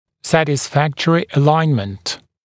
[ˌsætɪs’fæktərɪ ə’laɪnmənt][ˌсэтис’фэктэри э’лайнмэнт]удовлетворительное выравнивание (по форме зубной дуги)